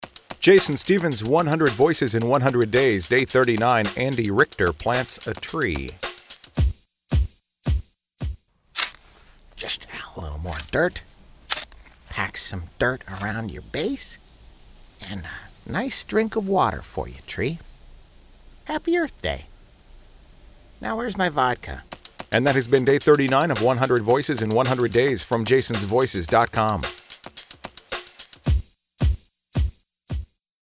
In honor of Earth Day, I’ve incorporated the environmentally-conscious act of planting a tree into today’s episode.  I’ve also incorporated an environmentally-conscious Andy Richter into the episode, as the one who is planting the tree.  I’m really happy with how it turned out – considering I hadn’t previously done an Andy Richter impression (nor did I set out to do one today).
But unfortunately, my throat/voice is a bit wonky due to the start of a cold or allergies – and I was having some difficulty dialing Harrison in.
Tags: Andy Richter voice match, celebrity impressions